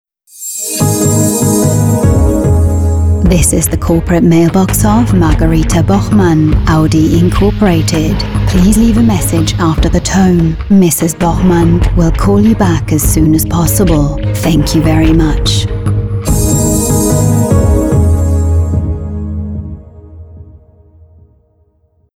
Junge, freundliche, warme, fröhliche englische Stimme für Voice Overs, Synchronisation und Werbung.
Sprechprobe: eLearning (Muttersprache):
A warm, friendly, young and happy sounding voice.